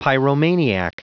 Prononciation du mot pyromaniac en anglais (fichier audio)
Prononciation du mot : pyromaniac